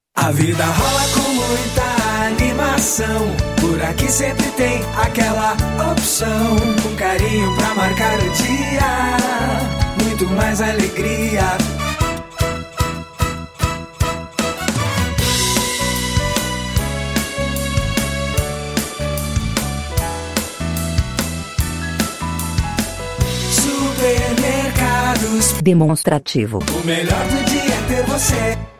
Spots e Jingles